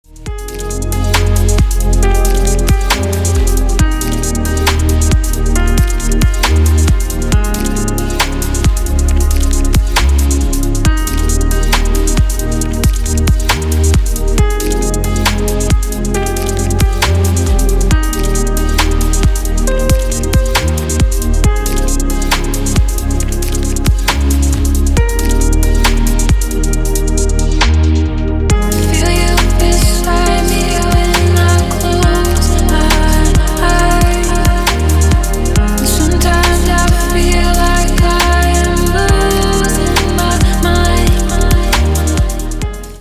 спокойная музыка для телефона